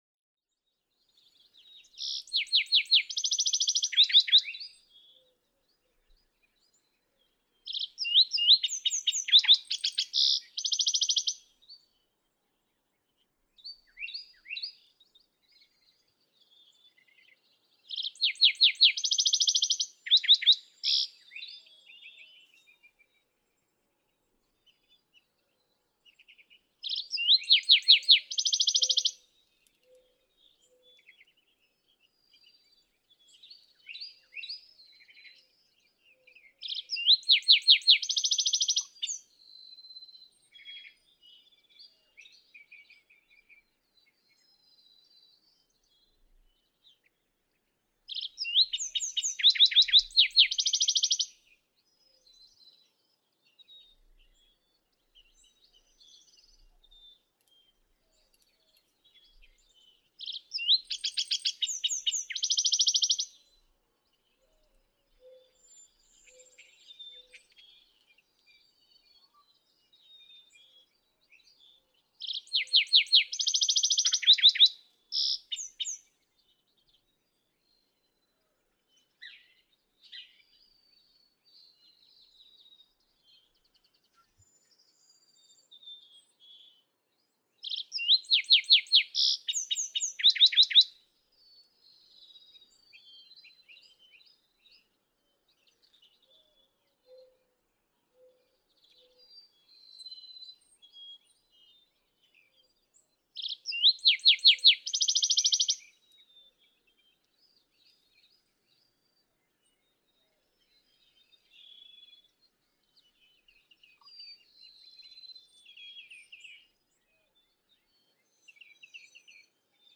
Lark sparrow
♫96. Normal song, rich and thrilling, one of the finest sparrow songs in North America.
Chaparral Wildlife Management Area, Artesia Wells, Texas.
096_Lark_Sparrow.mp3